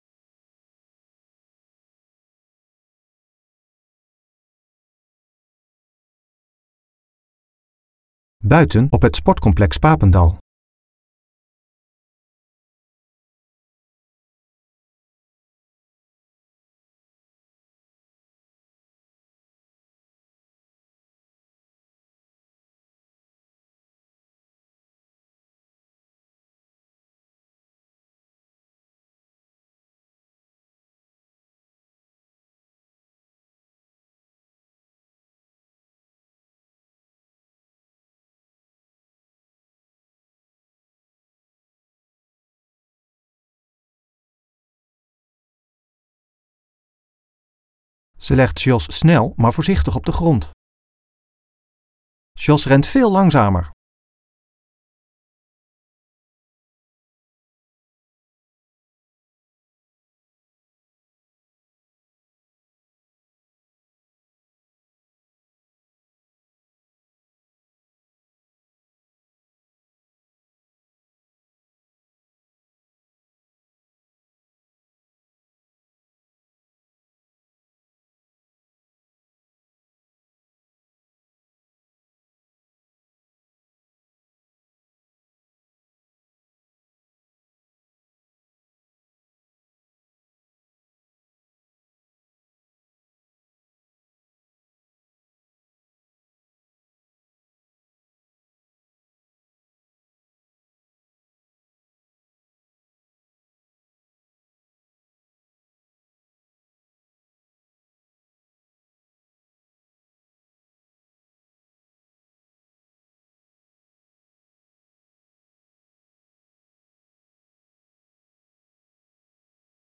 Zij gaat voor Vraagje?! de straat op en laat zien hoe makkelijk het is om het gesprek over toegankelijkheid te starten met een vraagje.